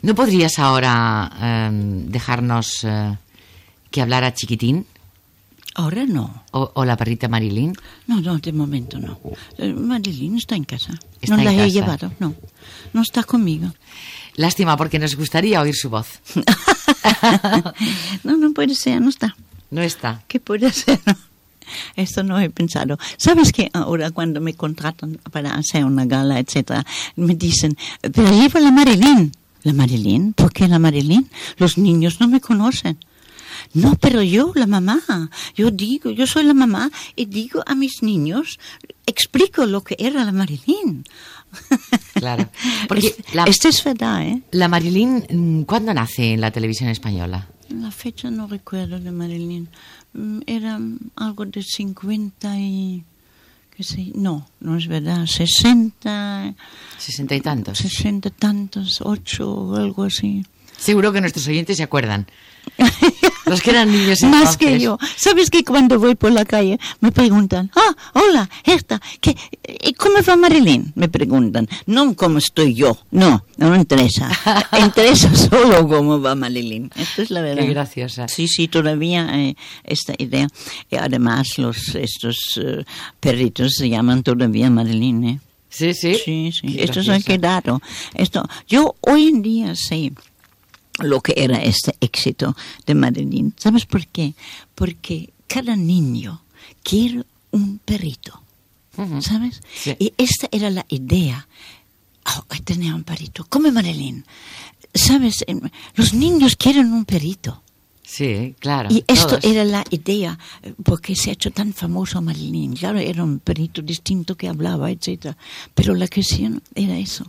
Entrevista a la marionetista Herta Frankel sobre els seus peluixos infantils, com La Perrita Marilín
Entreteniment